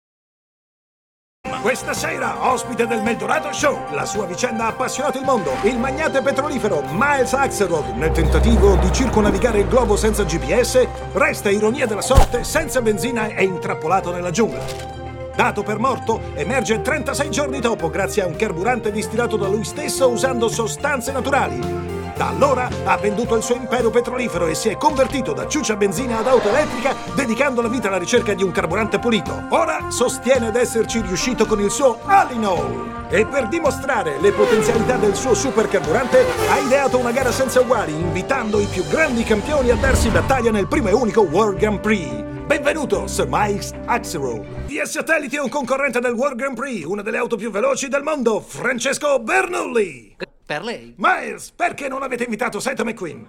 voce di Tiberio Timperi nel film d'animazione "Cars 2", in cui doppia Mel Dorado.